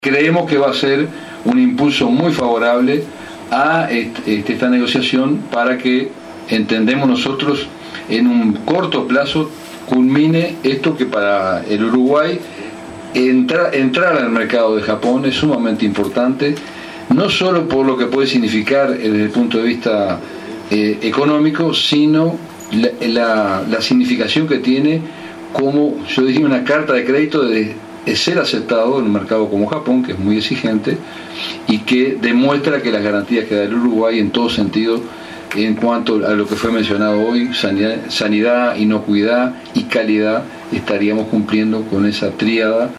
Escuche a Muzio